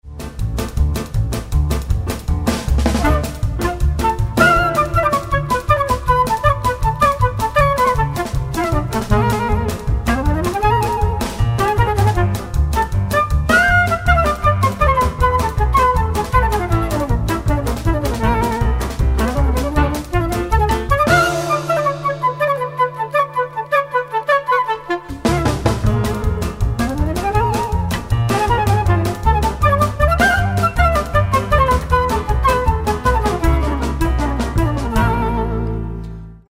Klezmer-Ensembles